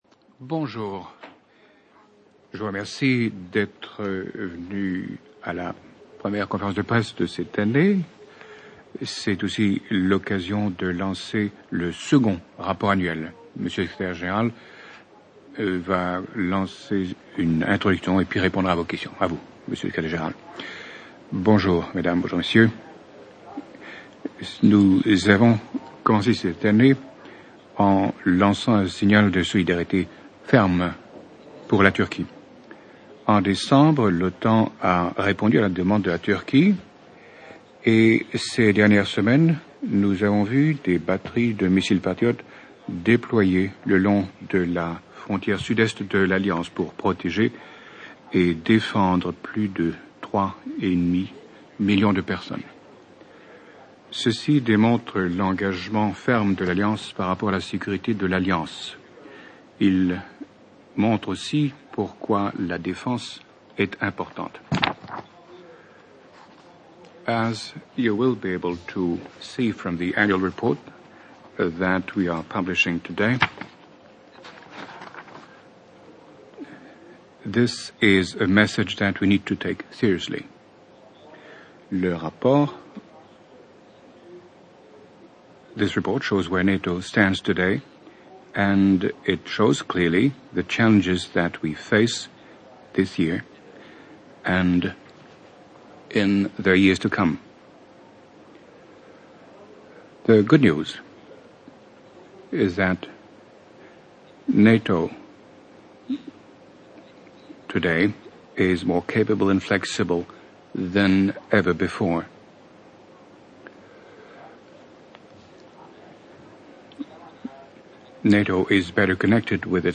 ORIGINAL - Press conference by NATO Secretary General Anders Fogh Rasmussen where he released his annual report for 2012 to the public 31 Jan. 2013 | download mp3 FRENCH - Press conference by NATO Secretary General Anders Fogh Rasmussen where he released his annual report for 2012 to the public 31 Jan. 2013 | download mp3